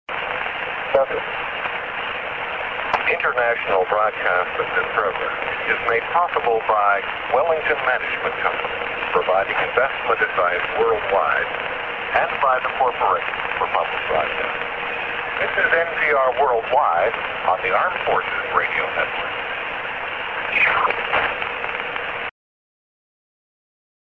Full ID